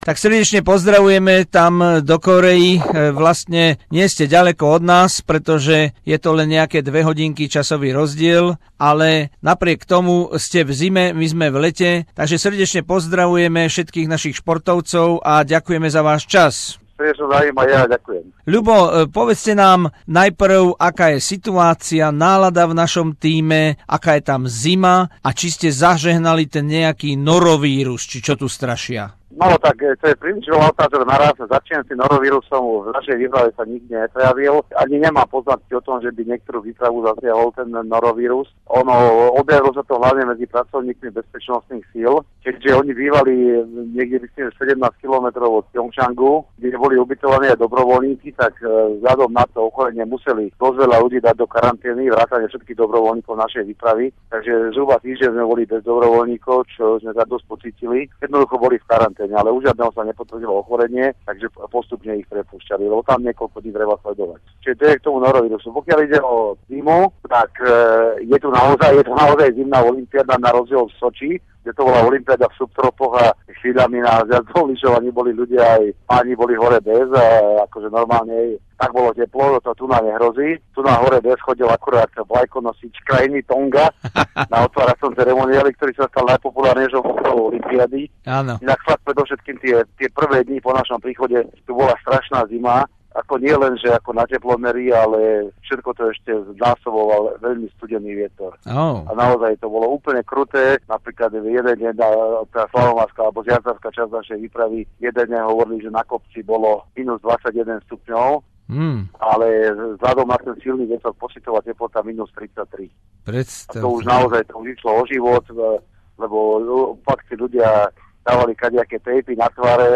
Exkluzívny rozhovor